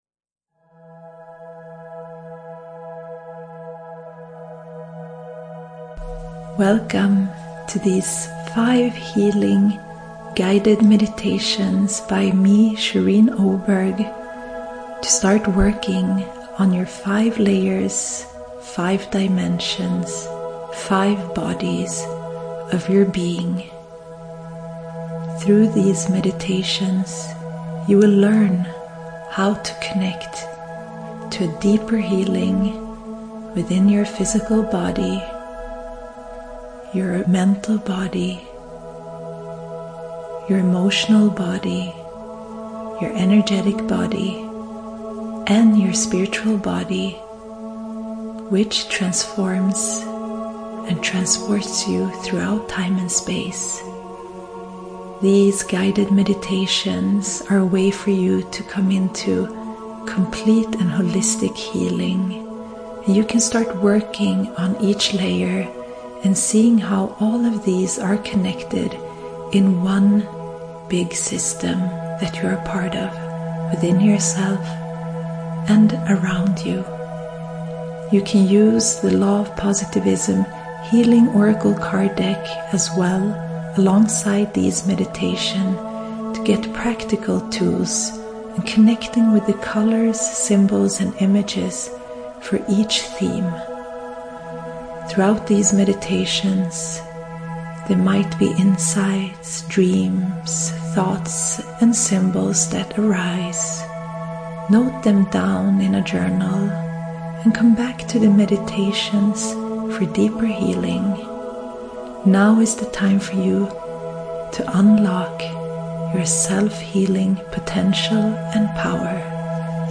The meditations can be listened to together for holistic healing or you can choose the one you most need in the moment. Be gently guided through soothing visualizations that give time and space to think about how you are feeling in each of the five areas, and help you to feel better.